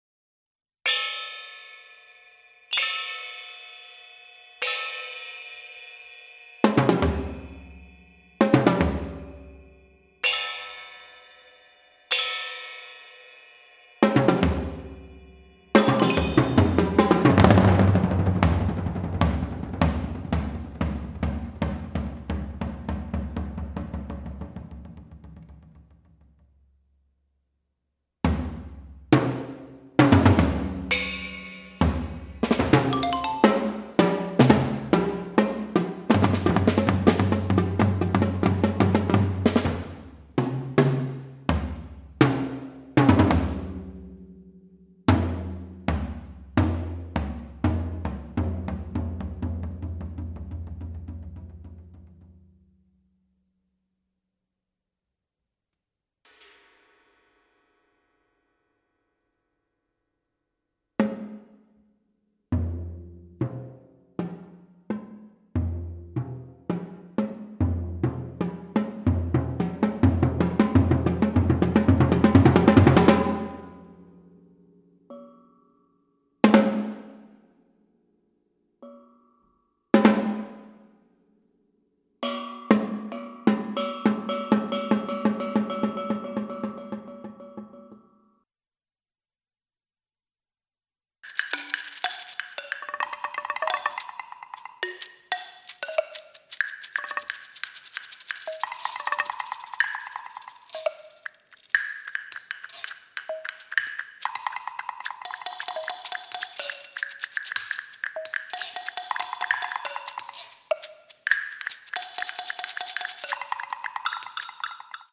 documenting never before heard chamber works